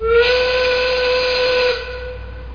WHISTLE.mp3